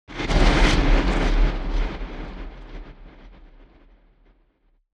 جلوه های صوتی
دانلود صدای طوفان 4 از ساعد نیوز با لینک مستقیم و کیفیت بالا